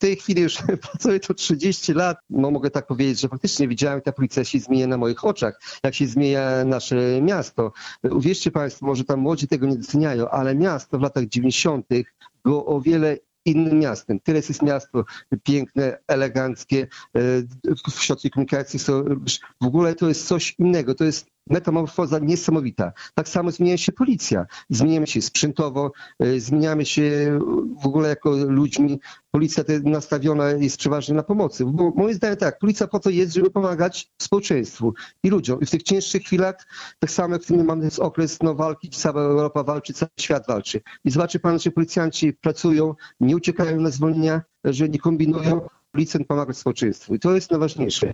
Podczas rozmowy jubilat opowiadał między innymi o swojej pracy. O zdarzeniach z jakimi się potykał i zmianach, jakie na przestrzeni lat zaszły w jego formacji i Suwałkach.